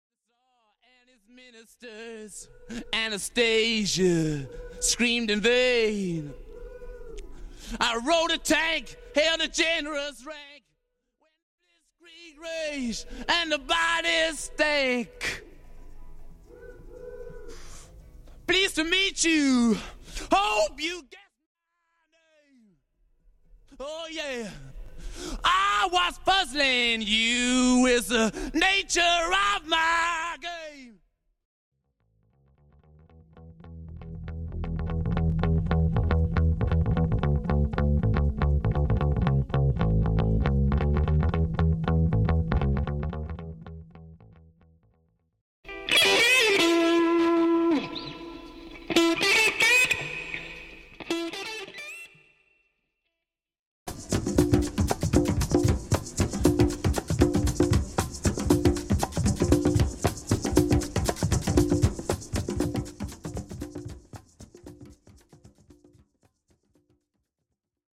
Bassline Guitar Stem
Percussion & Drums Stem
Piano Keys Stem